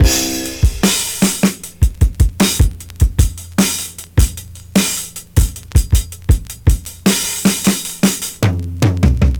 • 103 Bpm Drum Beat C Key.wav
Free drum groove - kick tuned to the C note. Loudest frequency: 3280Hz
103-bpm-drum-beat-c-key-j5K.wav